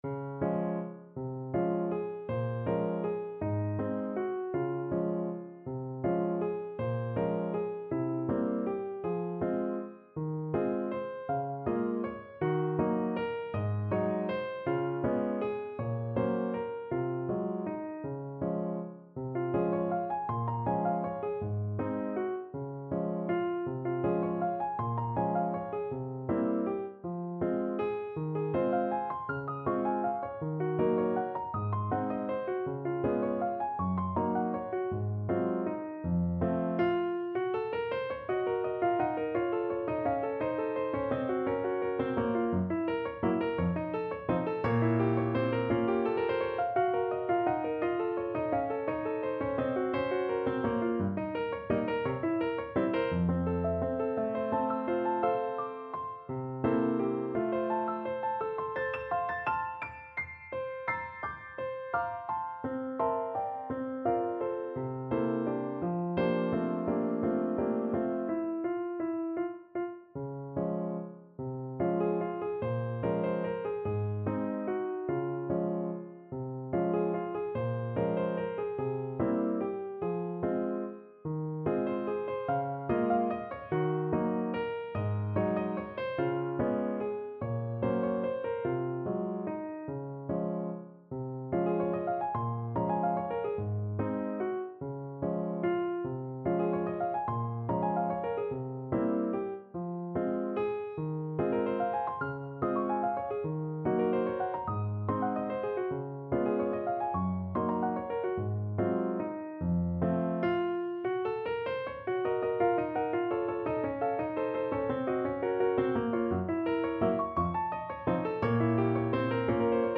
Free Sheet music for Piano
No parts available for this pieces as it is for solo piano.
Piano  (View more Intermediate Piano Music)
Classical (View more Classical Piano Music)